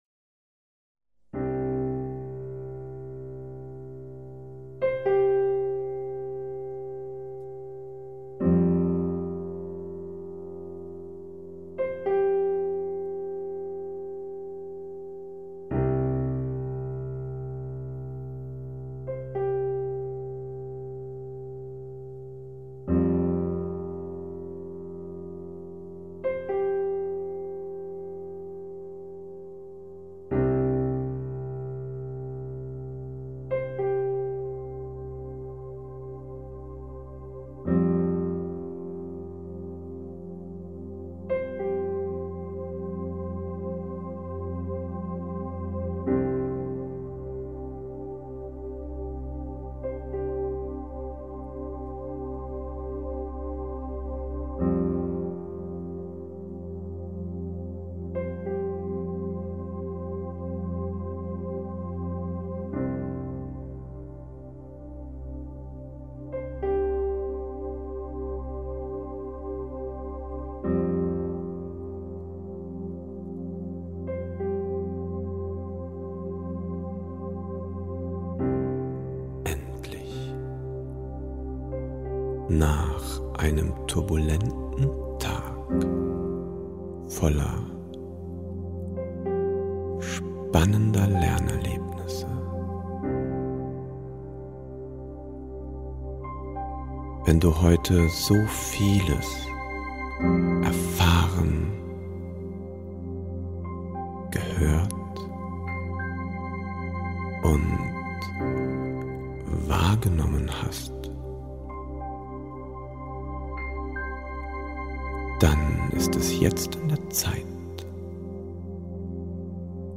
LERNEN IM SCHLAF – WISSEN SPEICHERN MIT HYPNOSE Diese geführte Hypnose hilft dir dabei, dein am Tag Gelerntes entspannt ins Langzeitgedächtnis zu überführen. Du musst nichts tun – außer dich hinlegen, loslassen & zuhören.